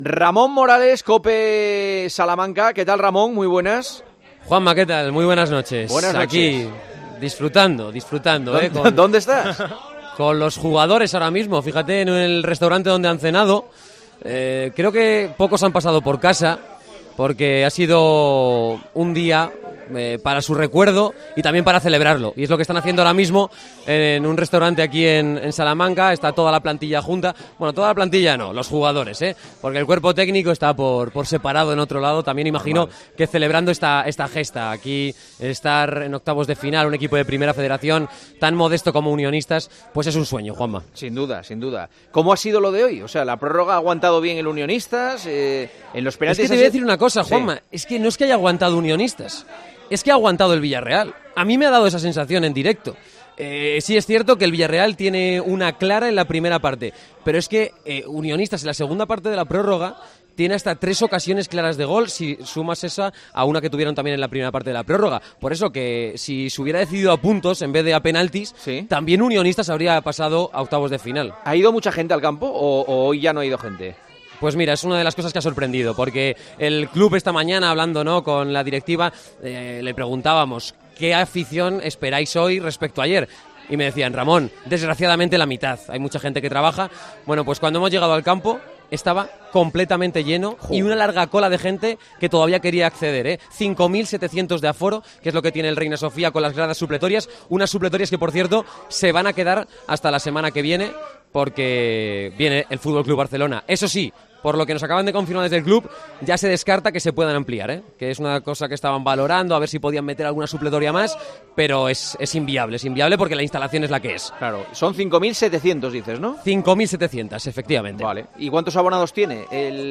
El micrófono de El Partidazo de COPE, presente en la cena de Unionistas tras clasificarse para octavos.